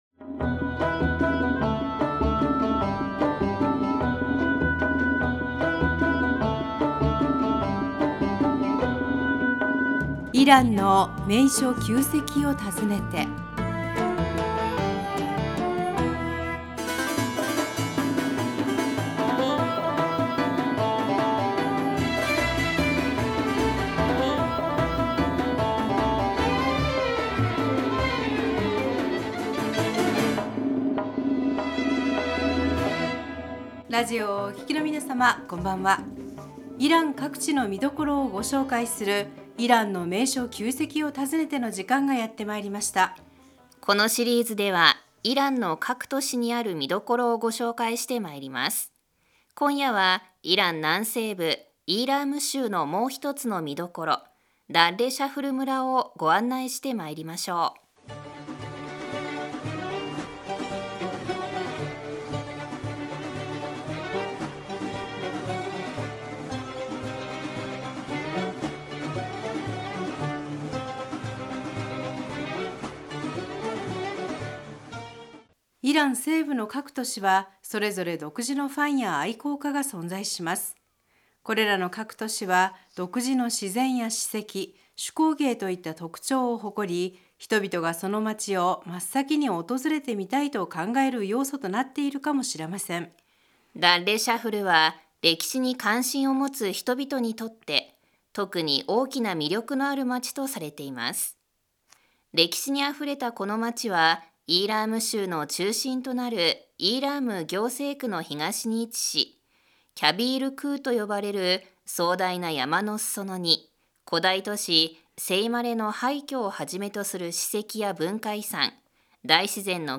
古代都市セイマレ それではここで、イーラーム州の古代都市セイマレからＩＲＩＢ記者の報告をお届けします。